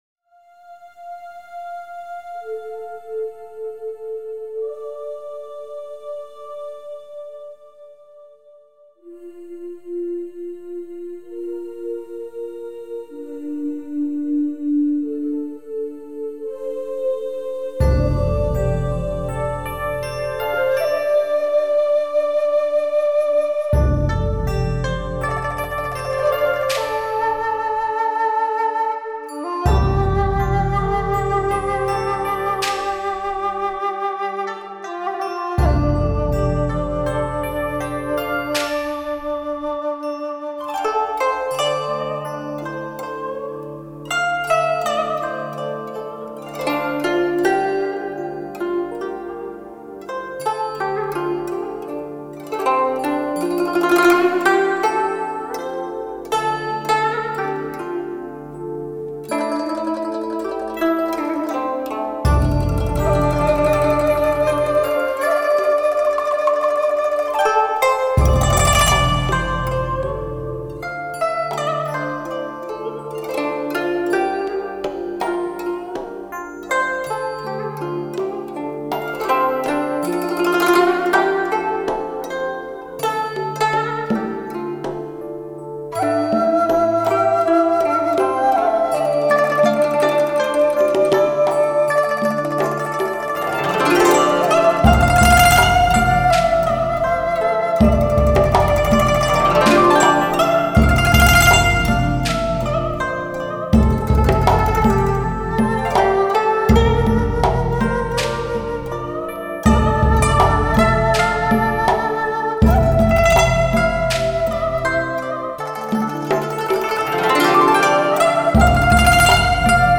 3周前 纯音乐 5